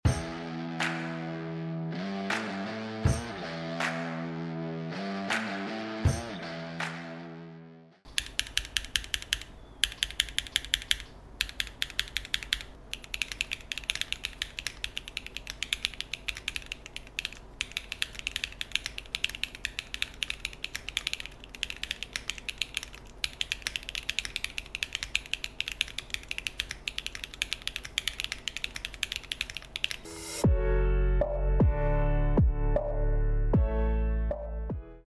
Soundtest switch Keygeek Explosion |